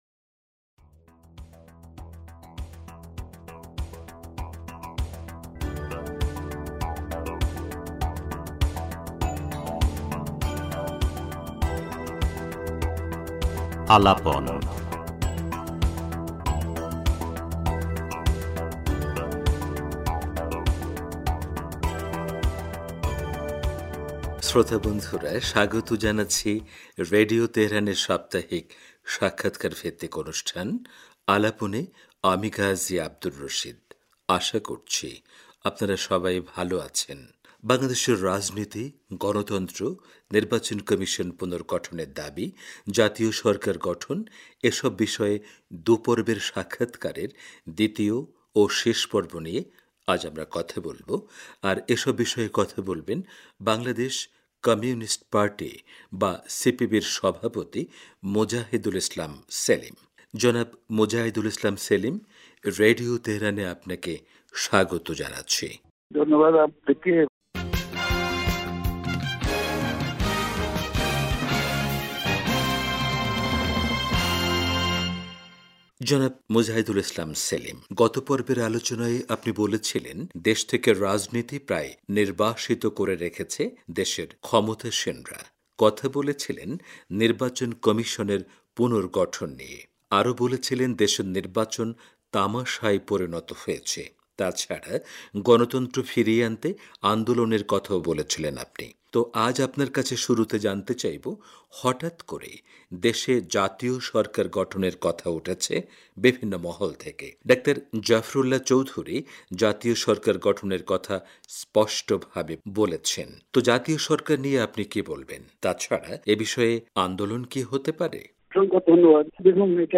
বাংলাদেশের রাজনীতি, নির্বাচন কমিশন পুনর্গঠনের দাবি, জাতীয় সরকার গঠন এসব বিষয়ে রেডিও তেহরানের সাথে কথা বলেছেন, বাংলাদেশের কমিউনিস্ট পার্টি বা সিপিবির সভাপতি...